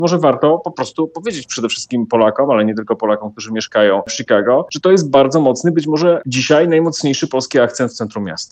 Jej autor, prof. Paweł Kowal, polityk, politolog, historyk i publicysta, mówi na antenie Radia Deon Chicago o związku rzeźbiarki z miastem Chicago, gdzie możemy zobaczyć największą instalację polskiej artystki.